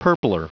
Prononciation du mot purpler en anglais (fichier audio)
Prononciation du mot : purpler